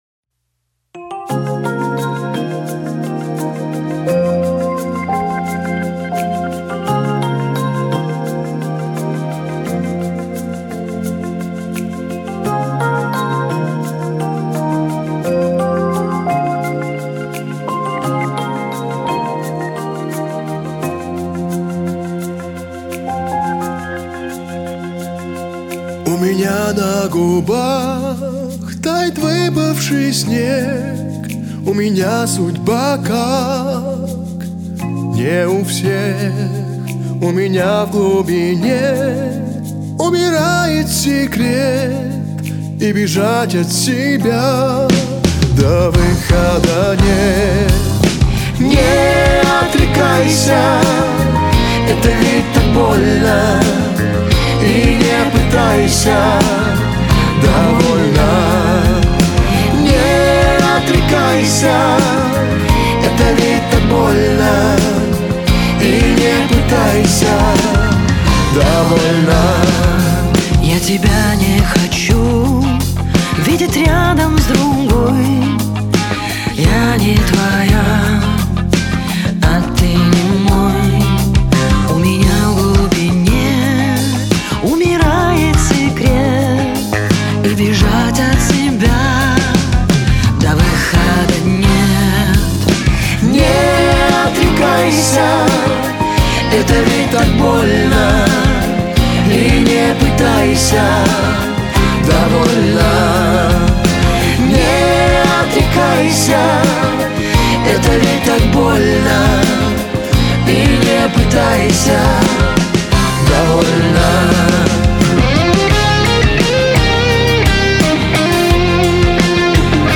это эмоциональная и мелодичная песня в жанре поп